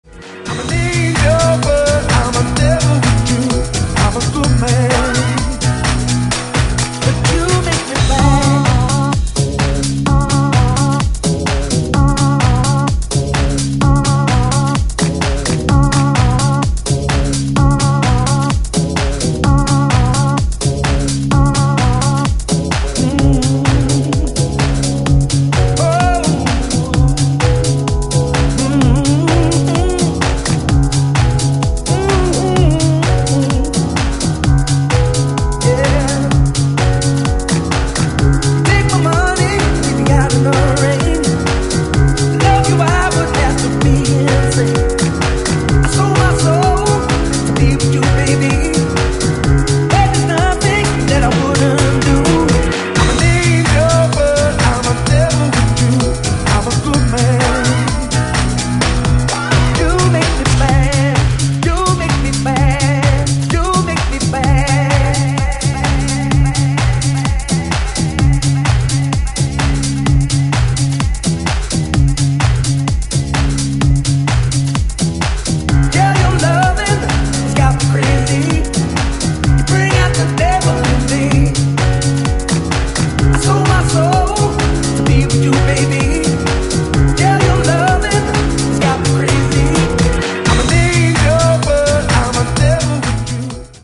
ジャンル(スタイル) HOUSE / DEEP HOUSE / DISCO HOUSE